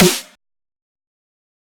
normal-hitclap.wav